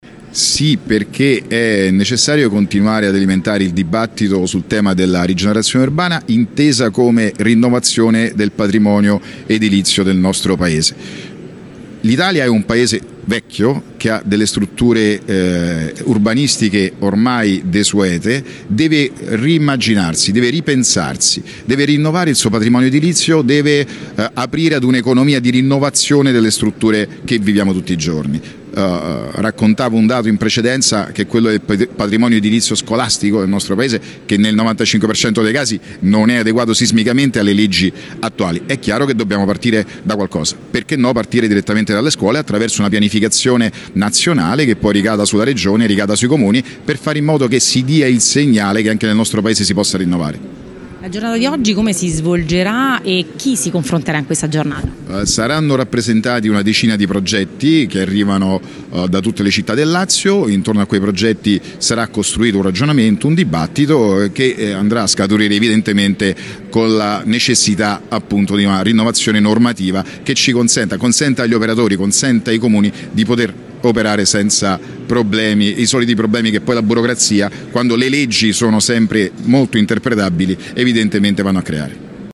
La tappa di Latina che andrà avanti per tutta la giornata è iniziata alle 10 al Museo Cambellotti.